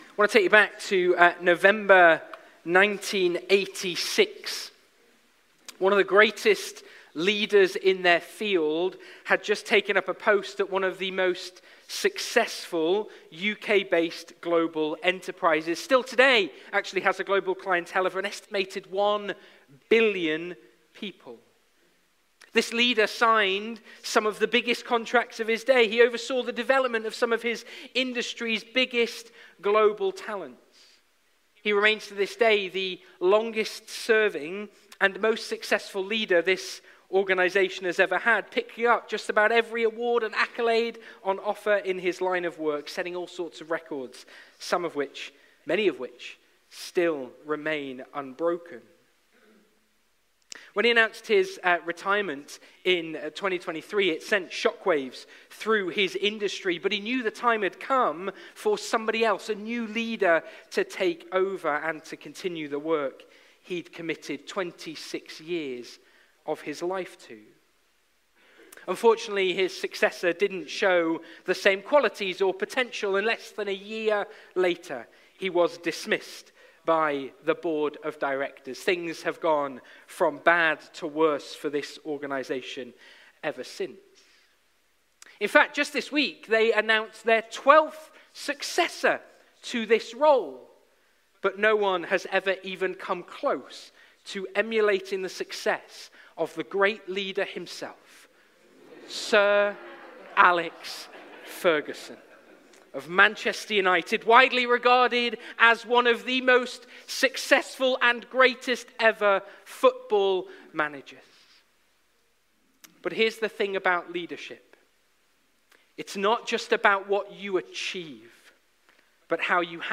Preparation for Promise Passage: Numbers 27:12-22 Service Type: Sunday Morning Download Files Notes « Paralysed by Fear What have we learnt?